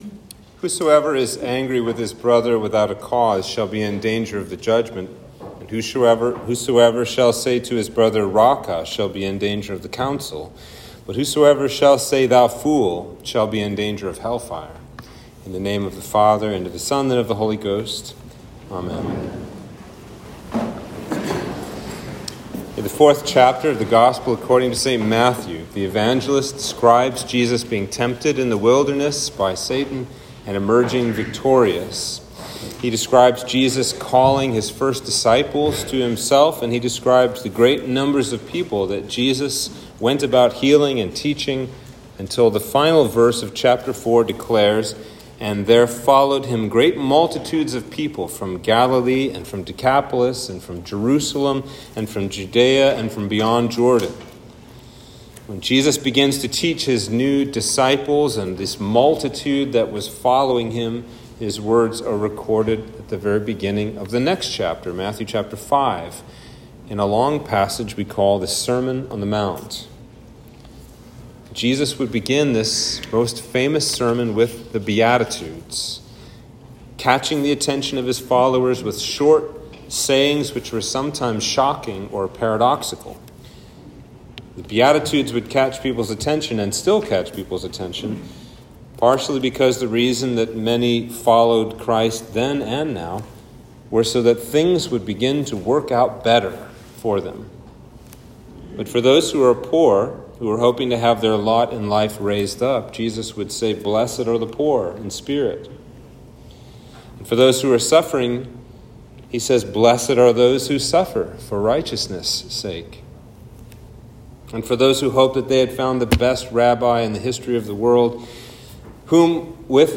Sermon for Trinity 6